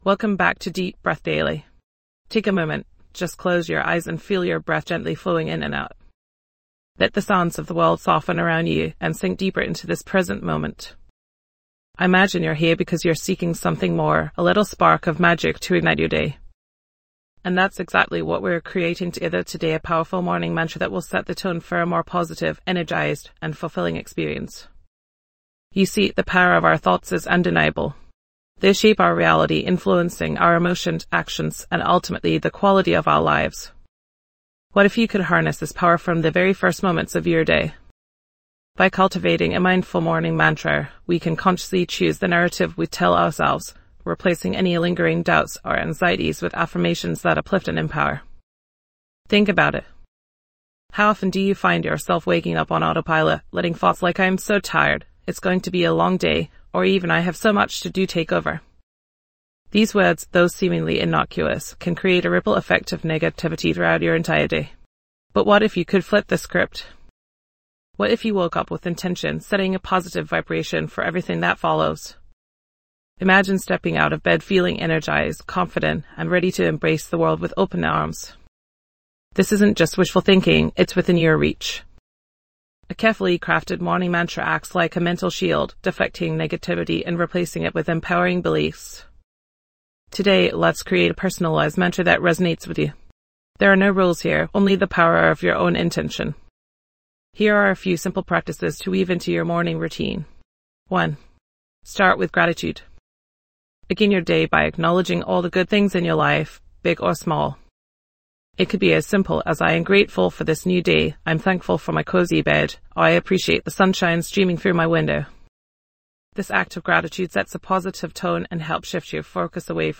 This podcast is your pocket-sized guide to incorporating mindfulness into your busy routine. With short, guided meditations and practical tips, we'll help you reduce stress, improve focus, and find moments of calm throughout your day.